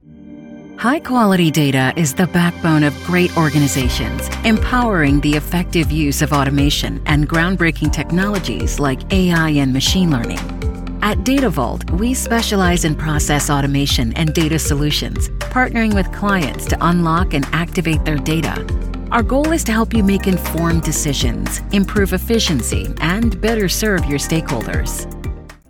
From energetic and fun to calm at mature.